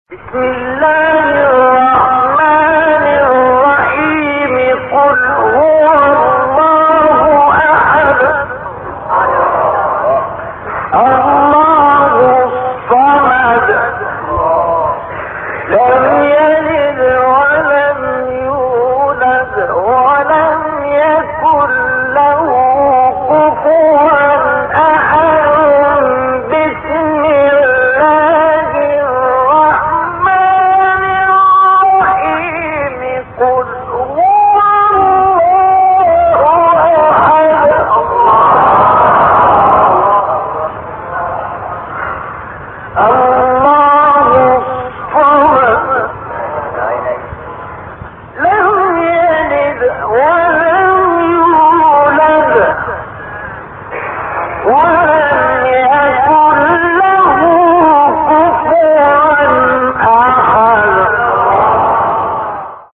تلاوت سوره اخلاص توسط استاد مصطفی اسماعیل | نغمات قرآن
سوره : اخلاص آیه : تمام سوره استاد : مصطفی اسماعیل مقام : مرکب خوانی (چهارگاه * رست) قبلی بعدی